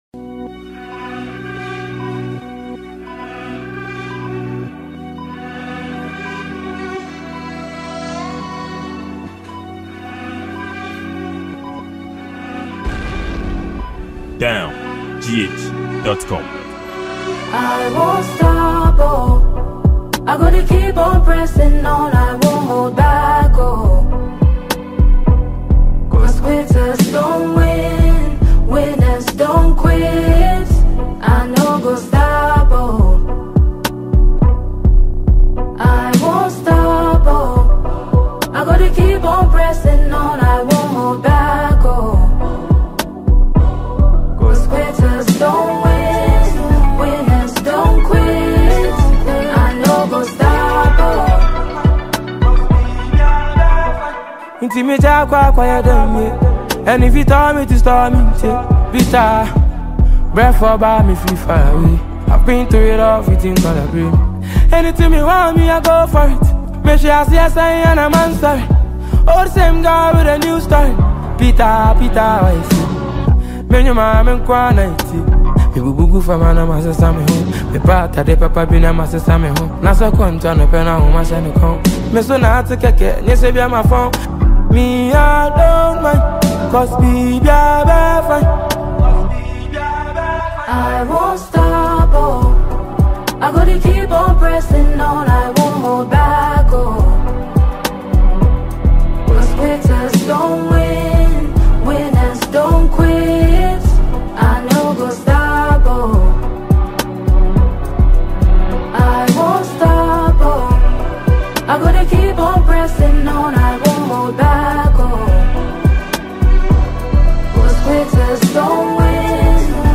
Ghanaian Highlife musician and songwriter
Ghanaian highlife music